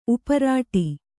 ♪ uparāṭi